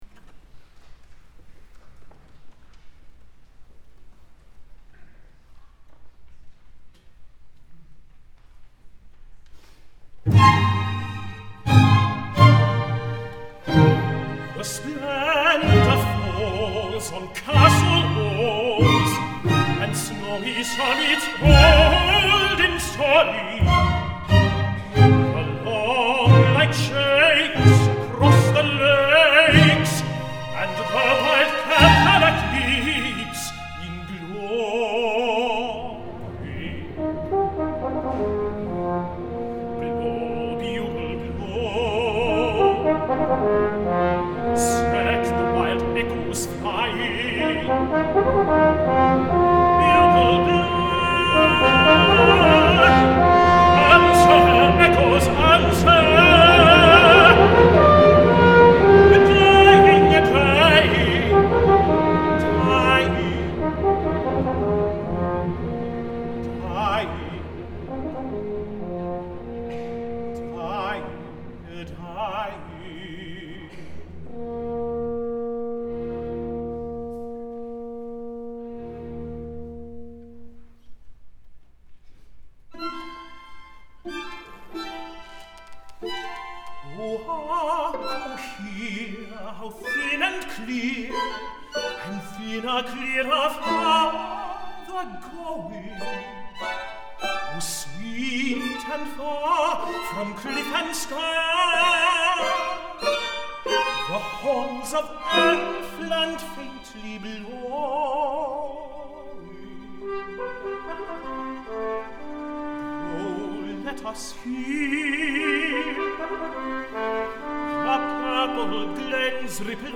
Please enjoy a few recordings of past performances
Serenade for Tenor, Horn, and Strings